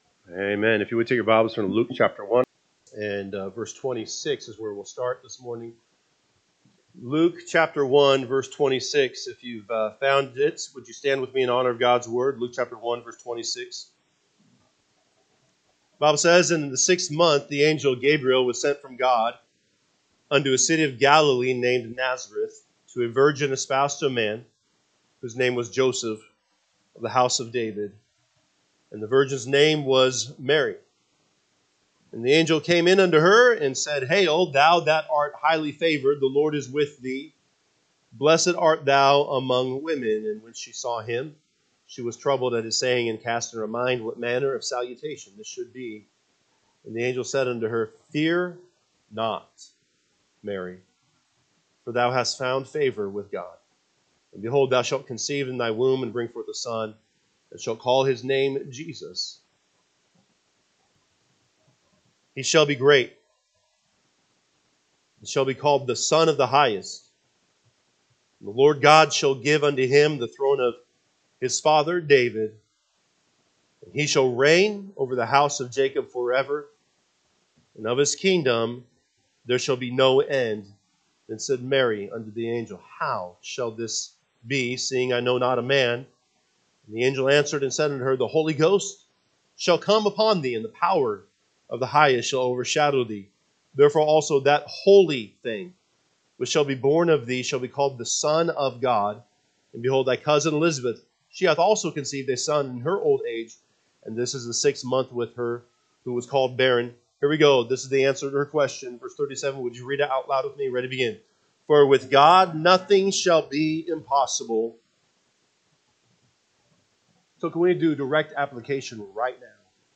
Sunday AM Bible Study